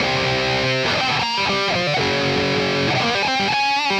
Index of /musicradar/80s-heat-samples/120bpm
AM_RawkGuitar_120-C.wav